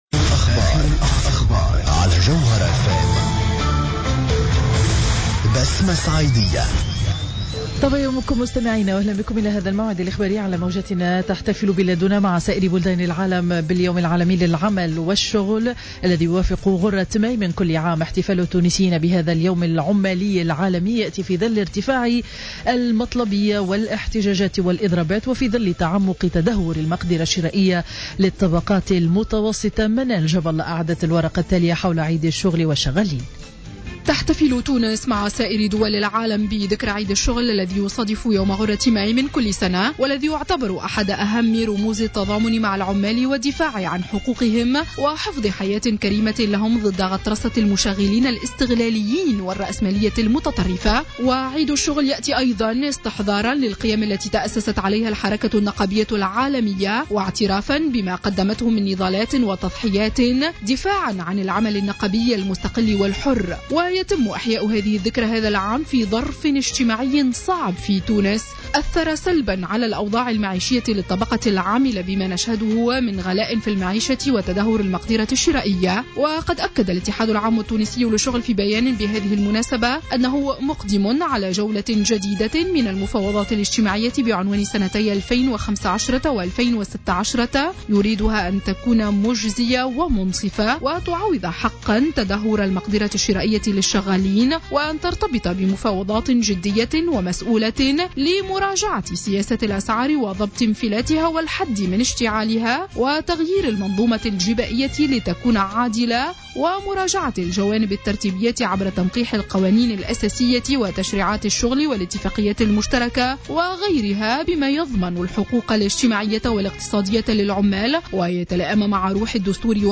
نشرة أخبار السابعة صباحا ليوم الجمعة 01 ماي 2015